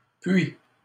ɥ huit,
Puy between wet and yet